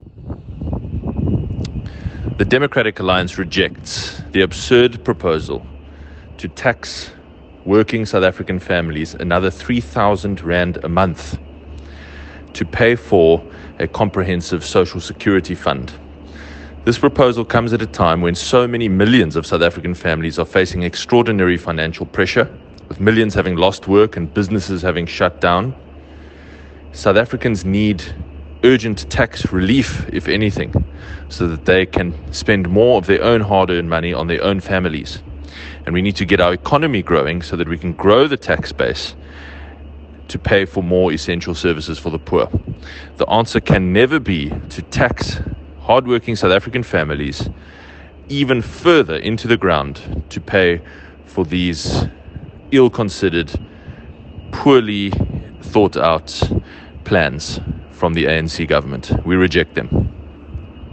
soundbite by Geordin Hill-Lewis MP